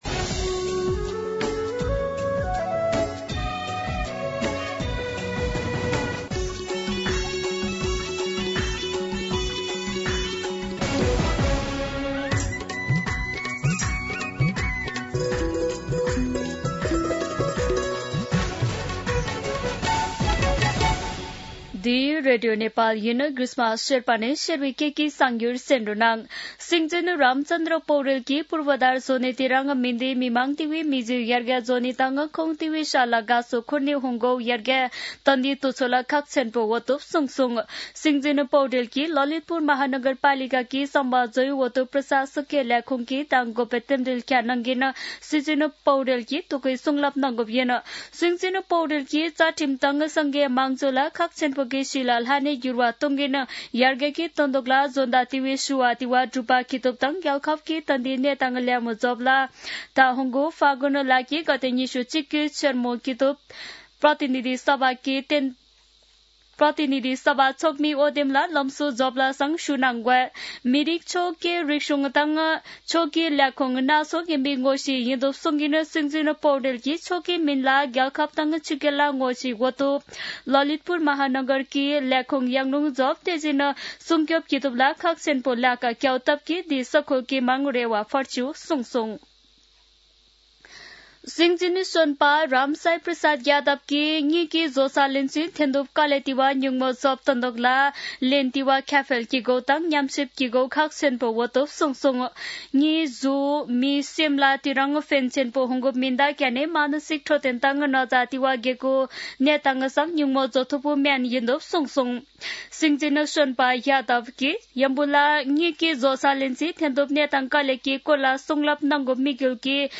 शेर्पा भाषाको समाचार : २० मंसिर , २०८२
Sherpa-News-8-20.mp3